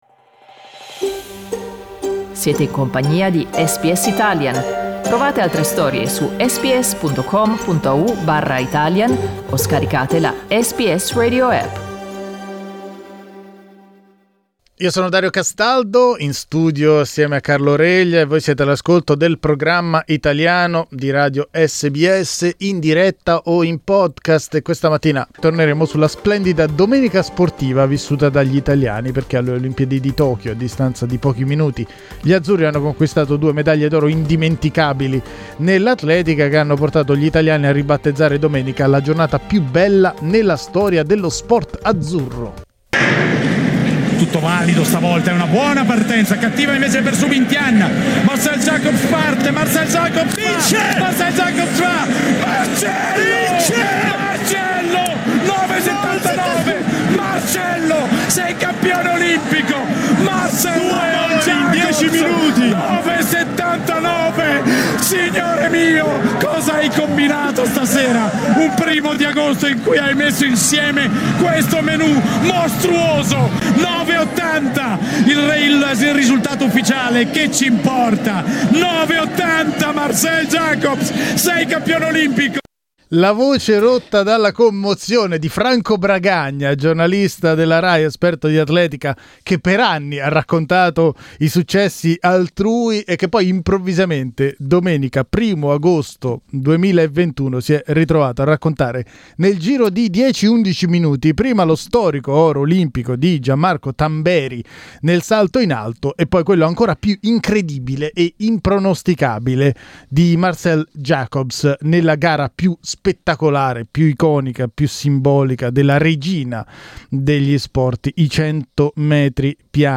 Domenica primo agosto è stata davvero la giornata più bella nella storia dello sport azzurro? Lo abbiamo chiesto ad addetti ai lavori e ascoltatori.